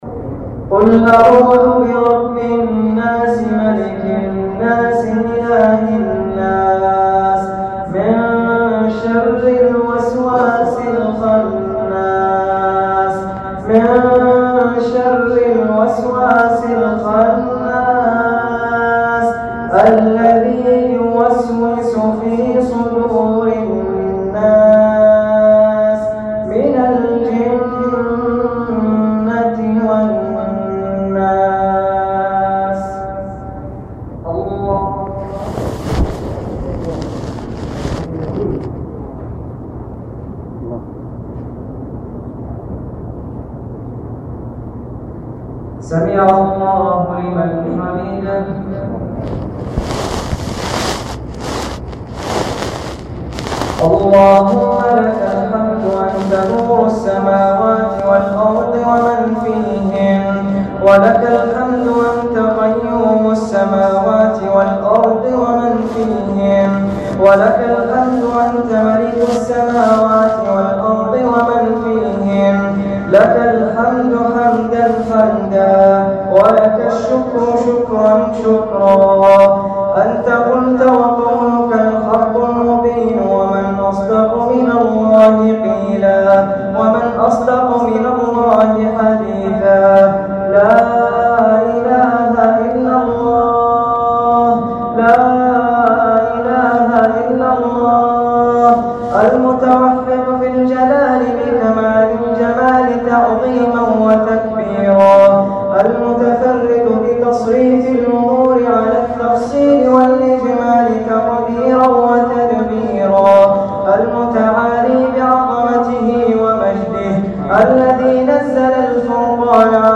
تسجيل لدعاء ختم القرآن الكريم من صلاة التهجد بمسجد الشيخ عبد الله لعام رمضان 1431هـ.